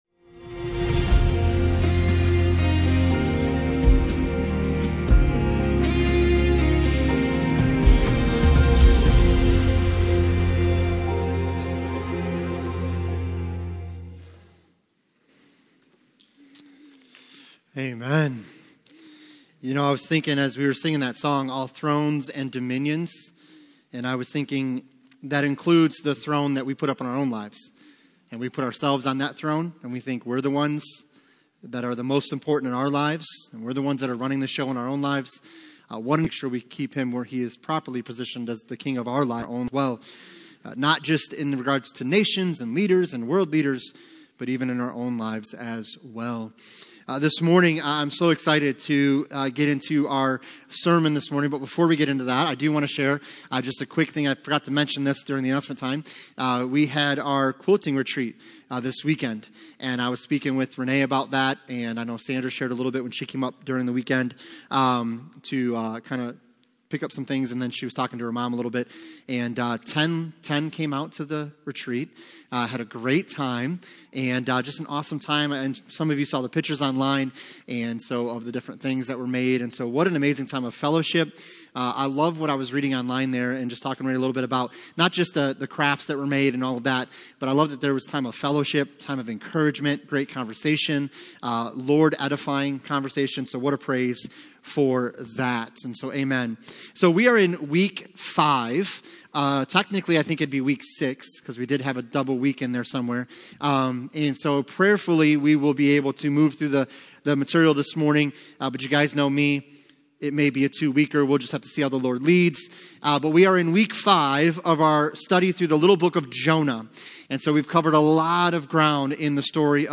Passage: Jonah 4:1-11 Service Type: Sunday Morning